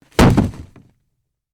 Free SFX sound effect: Single Shot Mat Throw.
Single Shot Mat Throw
Single Shot Mat Throw.mp3